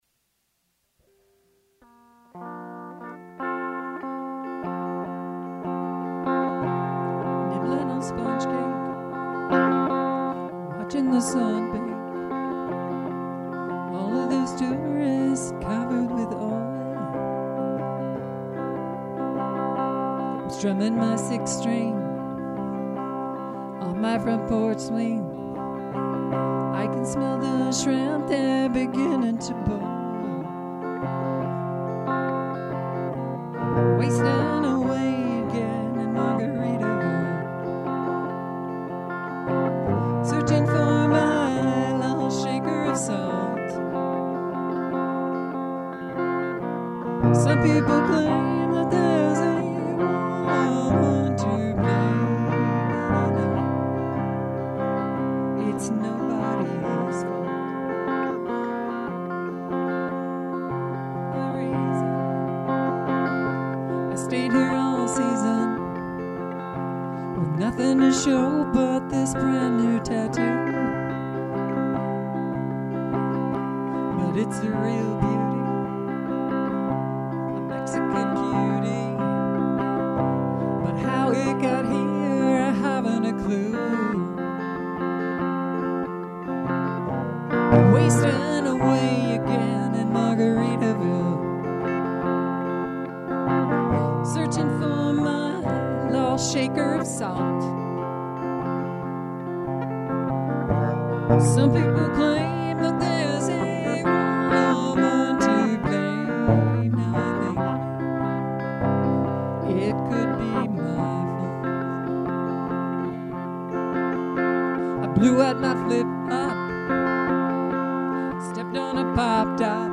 vocals
Guitar.